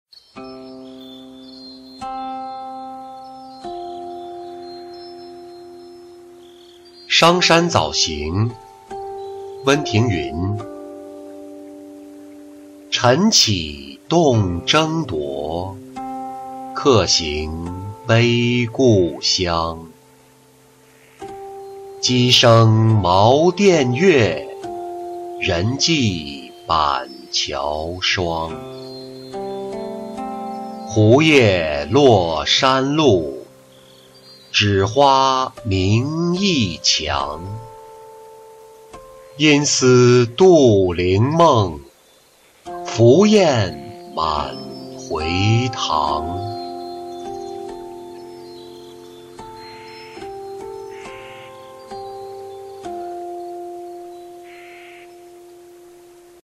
商山早行-音频朗读